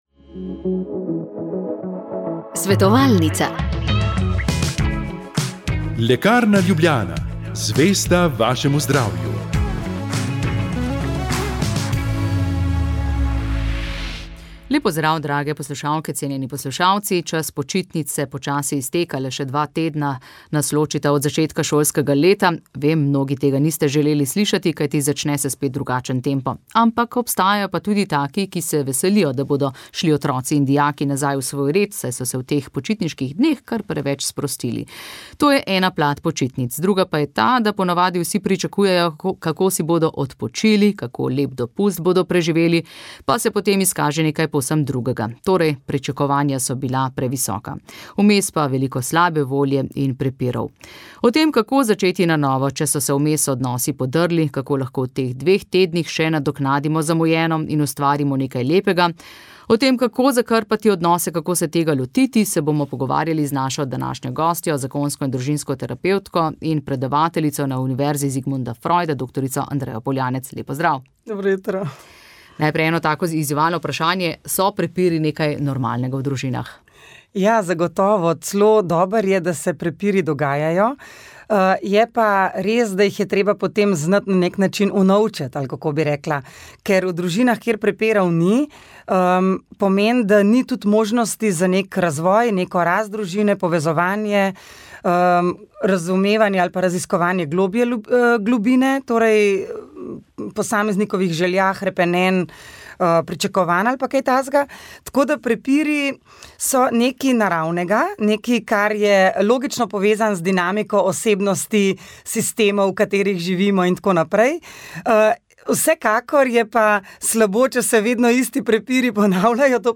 Molili so župljani župnije Ljubljana - Vič